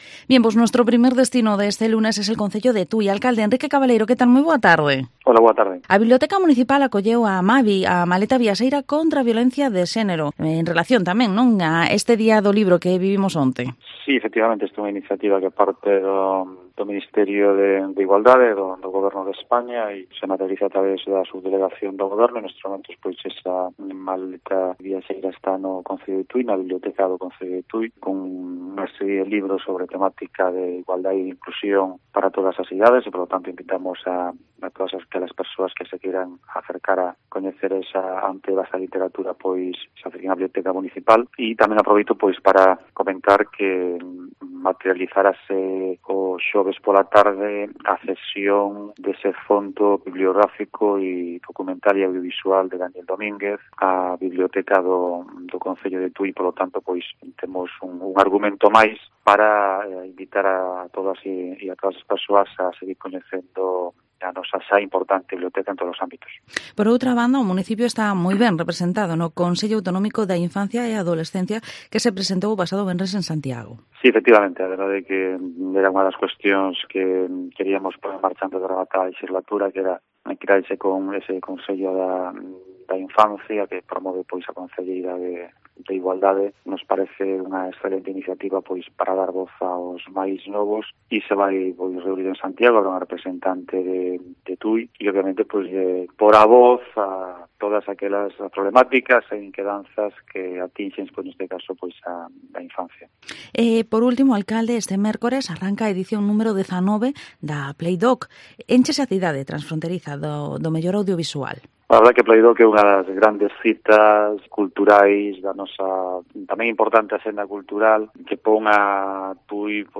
Entrevista al Alcalde de Tui, Enrique Cabaleiro